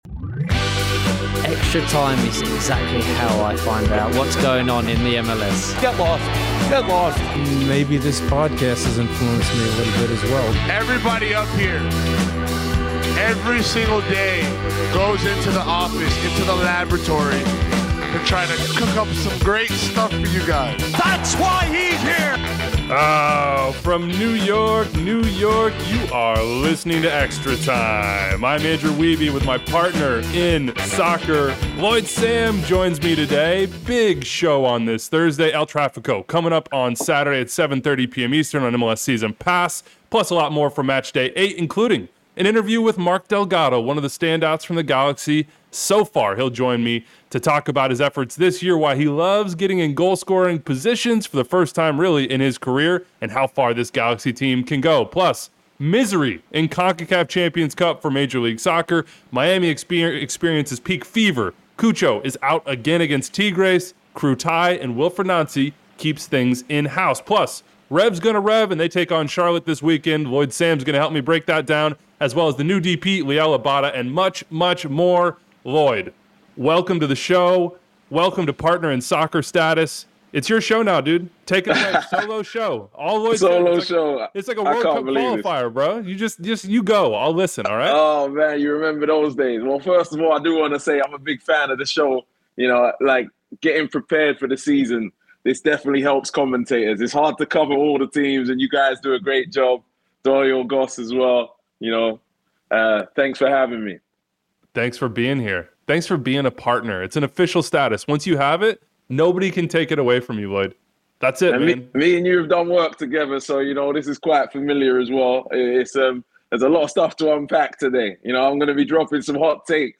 52:35 - Mark Delgado Interview